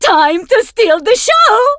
diva_ulti_vo_04.ogg